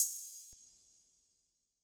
Closed Hats
Metro Hats [Nice].wav